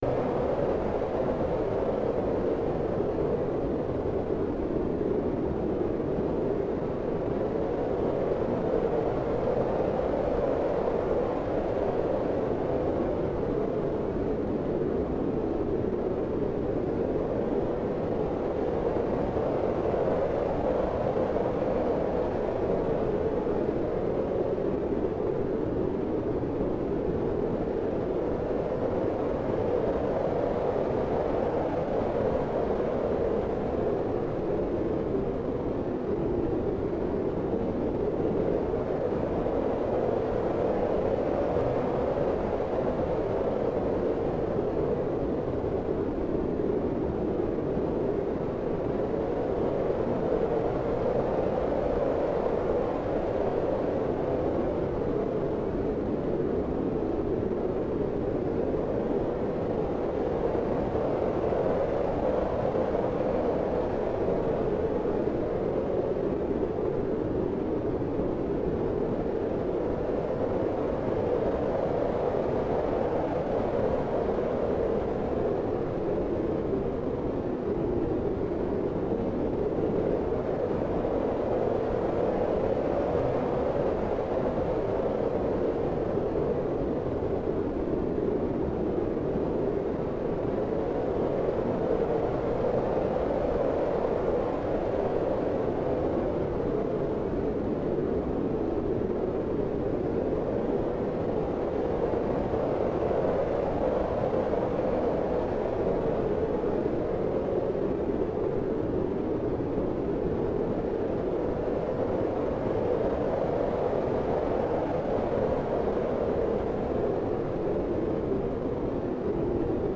Wind Sound in Market
midWind.wav